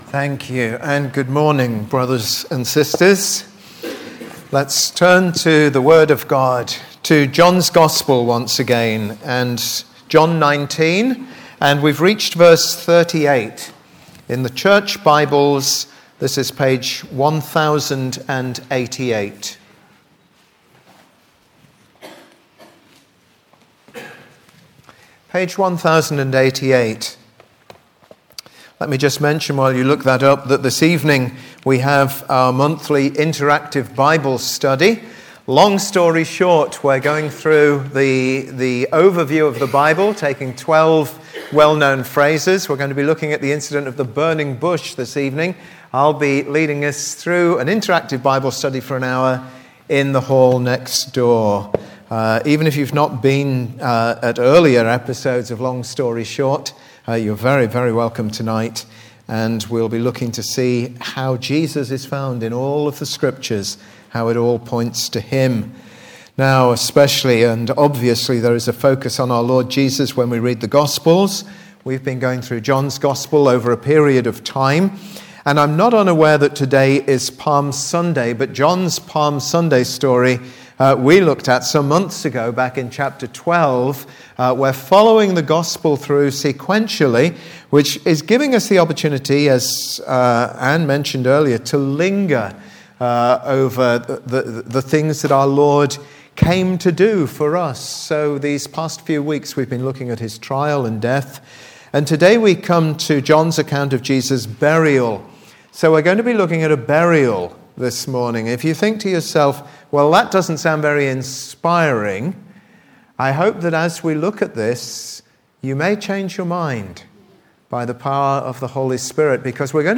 Passage: John 19:38-42 Service Type: Sunday 11:15 Bible Text